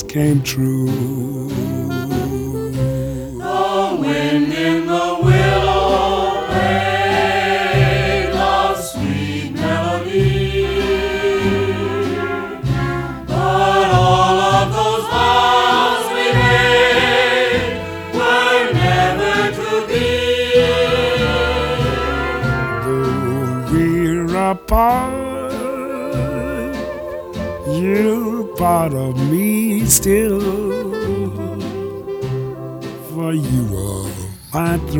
Саксофон и лёгкий свинг
Jazz Vocal Trad Jazz Dixieland Vocal Jazz Traditional Pop
Жанр: Джаз